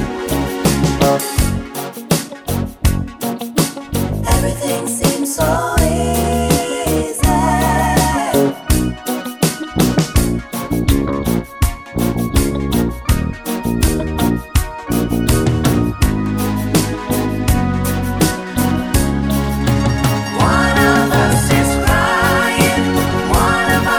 One Semitone Down Pop (1970s) 4:09 Buy £1.50